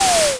portal_spawn.wav